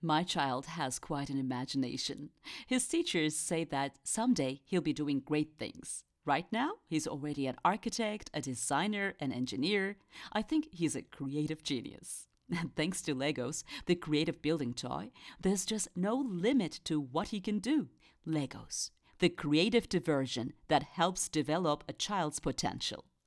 Reklam / Tanıtım
Kadın | Genç